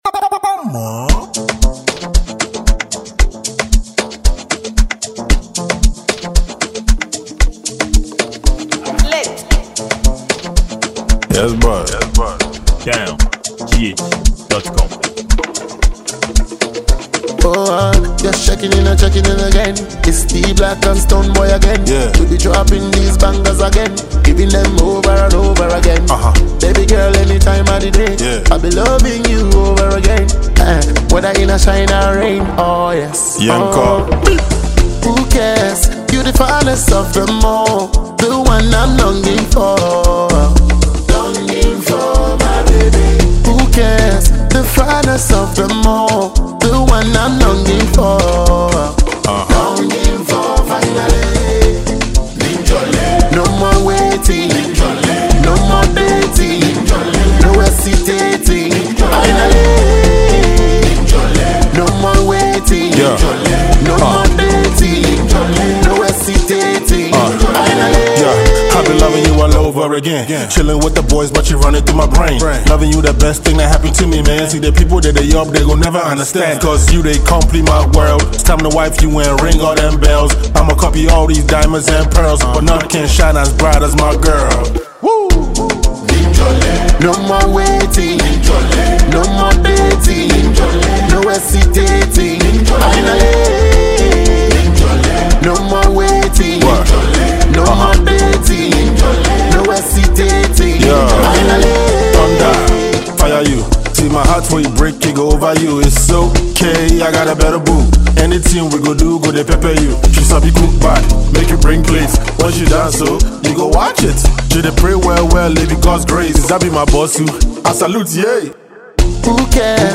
featured Ghanaian afrobeat dancehall artist